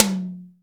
RS TOM 1-4.wav